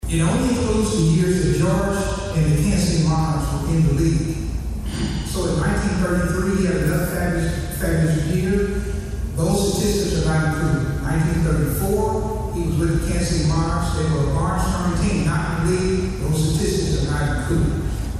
The Negro Leagues and its ties to the Manhattan area were featured as part of the kickoff to Juneteenth weekend festivities Thursday night at the Douglass Recreation Center.